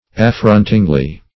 affrontingly - definition of affrontingly - synonyms, pronunciation, spelling from Free Dictionary Search Result for " affrontingly" : The Collaborative International Dictionary of English v.0.48: Affrontingly \Af*front"ing*ly\, adv. In an affronting manner.